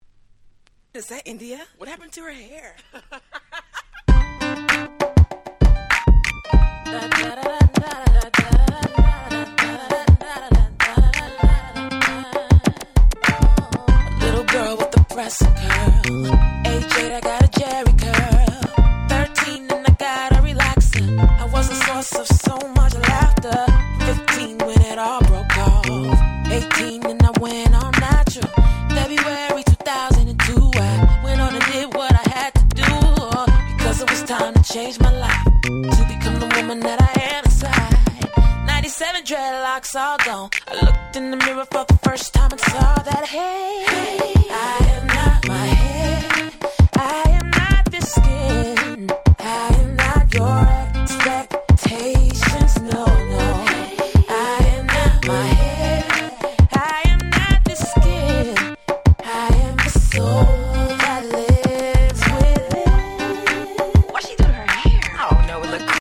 ※試聴ファイルは別の盤から録音してございます。
06' Smash Hit R&B / Neo Soul !!
それぞれフロア向けのなかなか良いHip Hop Soulに仕上がっております。